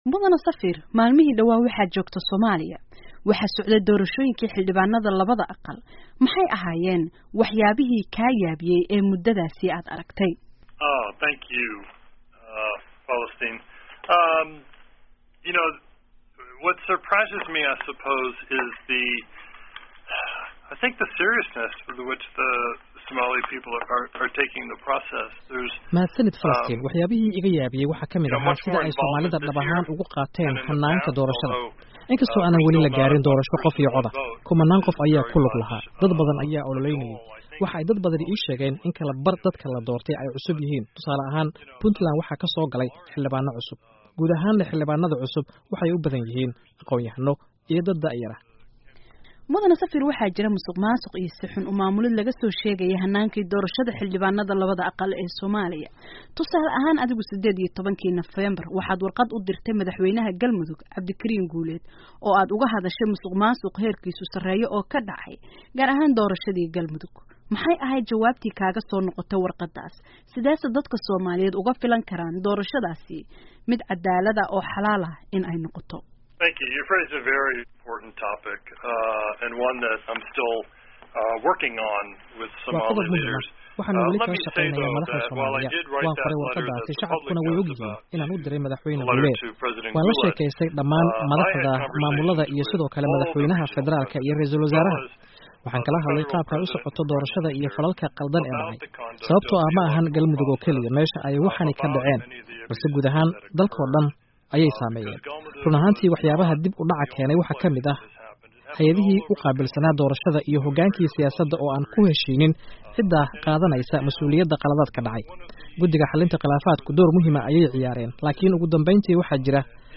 22 dec 2016 (Puntlan) Safiirka Mareykanka ee Soomaaliya Stephen Schwartz oo waraysi dheer siiyey VOA ayaa ka hadlay Hanaanka Doorashada ee Soomaaliya, Caqabadaha jira iyo aragtida dowlada Mareykanka ee Doorashooyinka Soomaaliya.